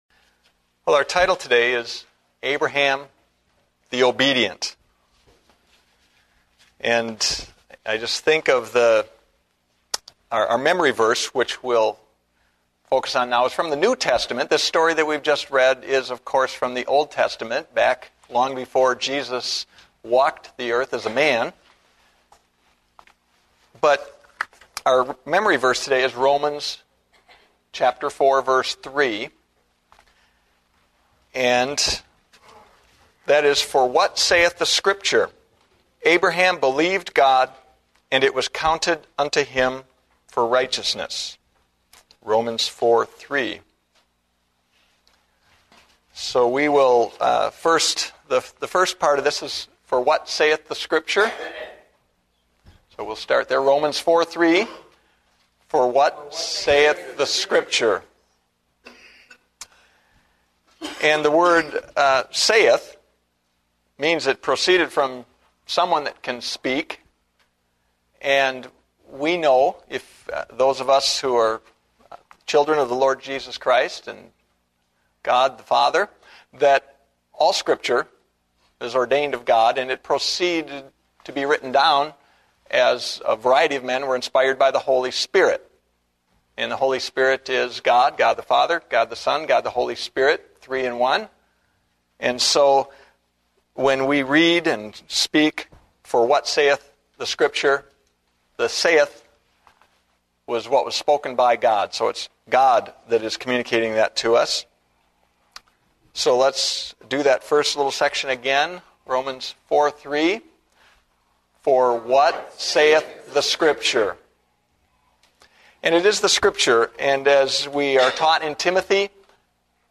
Date: February 22, 2009 (Adult Sunday School)